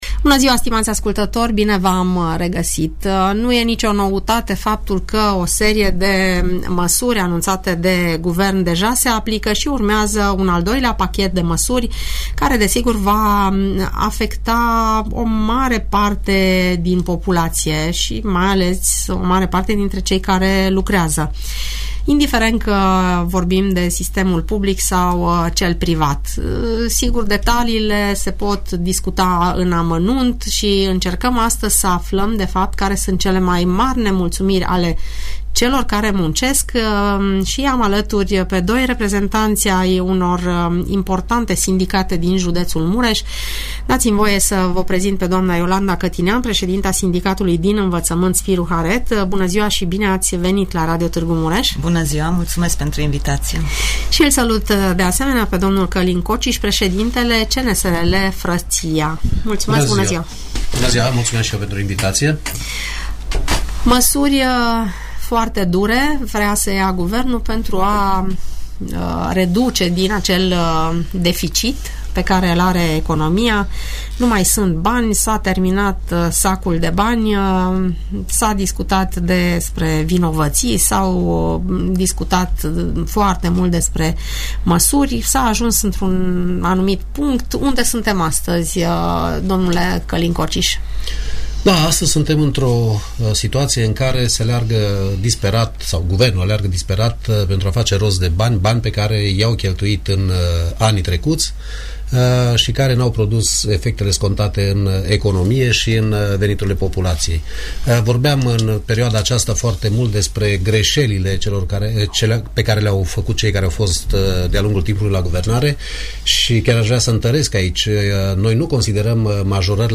Află detalii, urmărind dialogul moderat